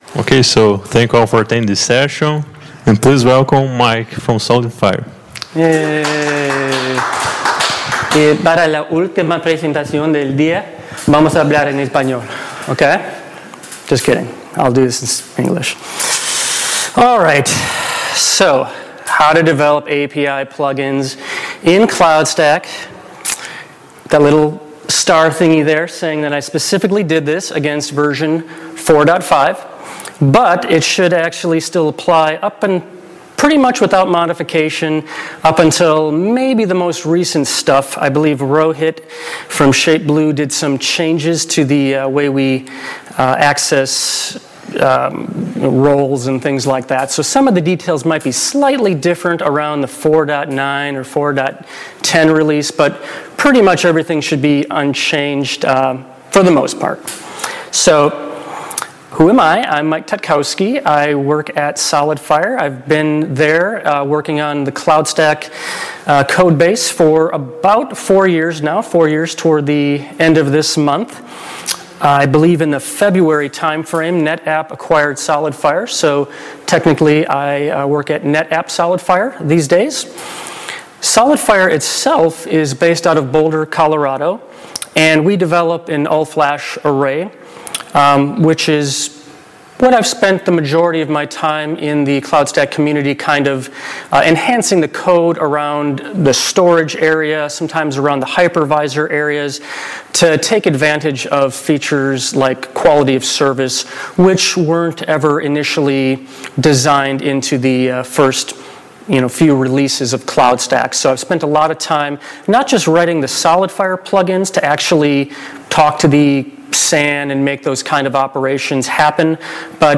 I walk the audience through a real-world example that demonstrates a business case for extending CloudStack’s API.